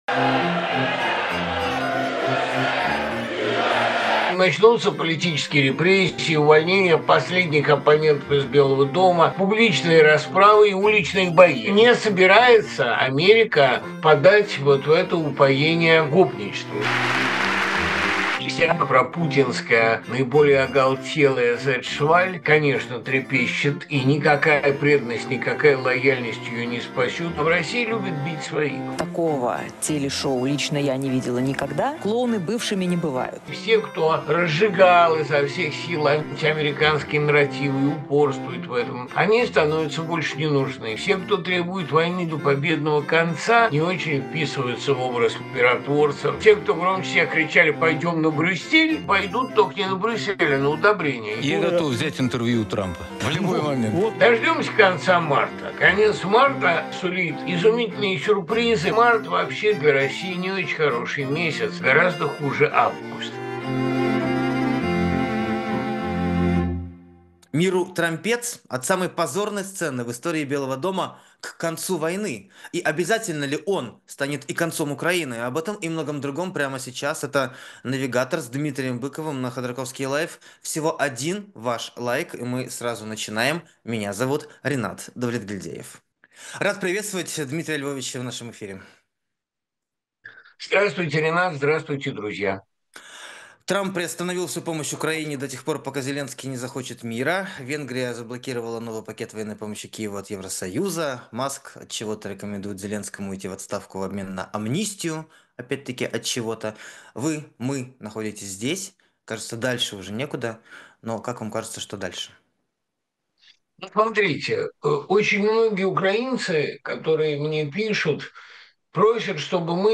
«Навигатор» с Дмитрием Быковым: Путин воюет дальше. Мира не будет, а Третья мировая? Трамп и раскол Америки. Z против Шамана